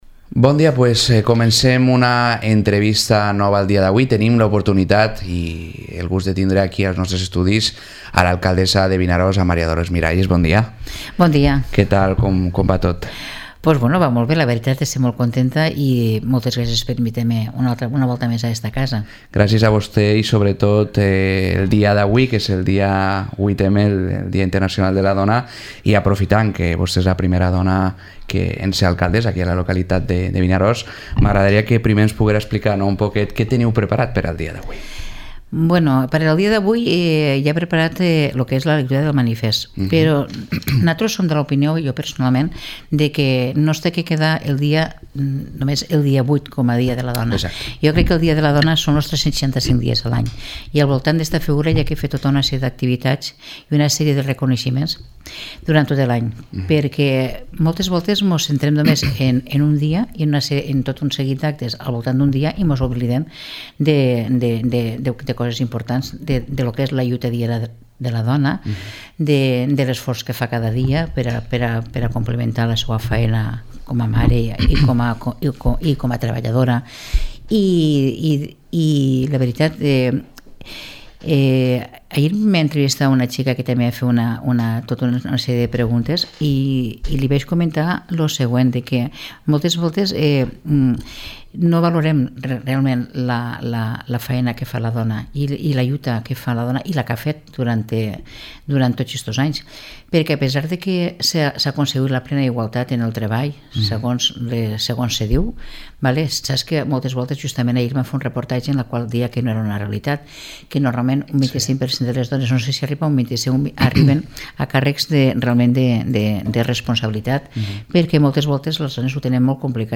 Entrevista a Maria Dolores Miralles, alcaldessa de Vinaròs